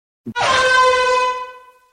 lethal company airhorn
lethal-company-air-horn-made-with-Voicemod-technology.mp3